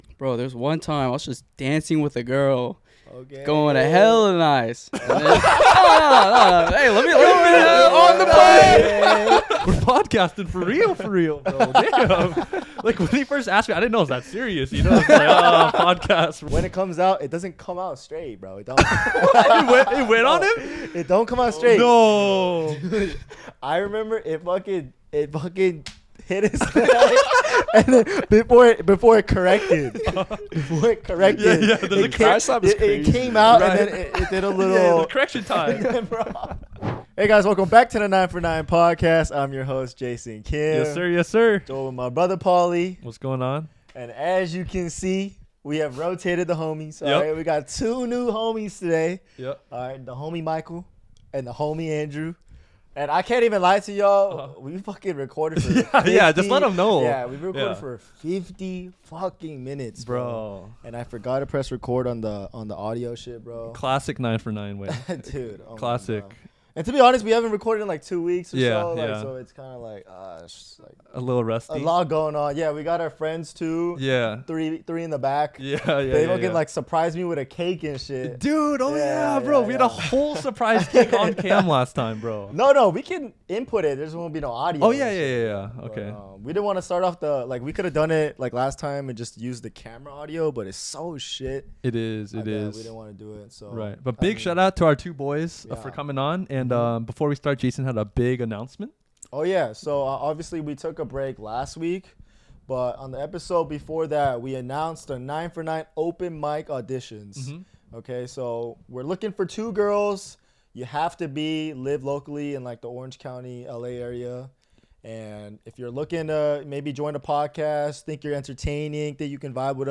A podcast hosted by a couple college kids talking about relationships, life, and much more.